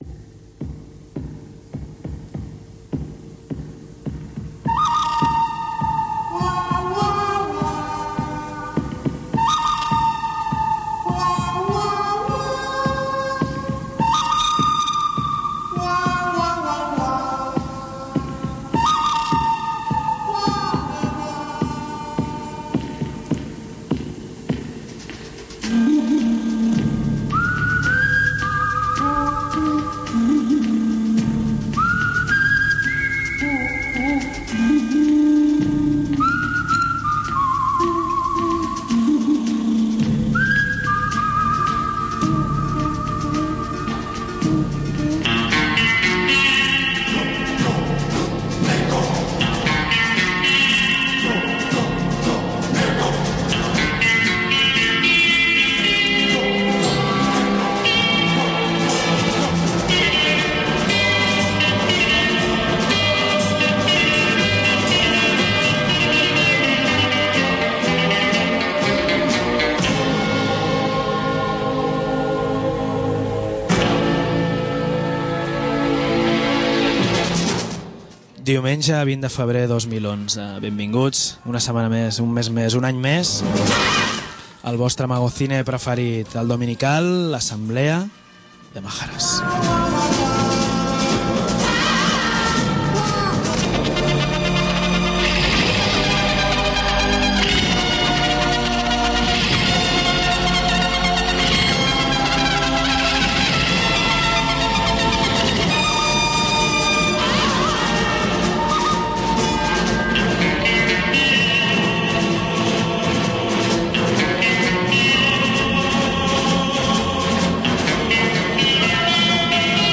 Per fi tenim amb nosaltres els companys de Ràdio Rsk, que ens comenten els devenirs, el passat i sobretot el present d’aquesta ràdio lliure del Turó de la Peira. Aprofitem per parlar de la Coordinadora de Ràdios Lliures de la que formen part i de notícies recents d’altres ràdios membres: les dificultats i el concert per l’autogestió (dissabte 26F al Rock’n’Trini) de Ràdio Linea IV i l’absolució de Ràdio Pica en el judici per injúries contra AM2 FM.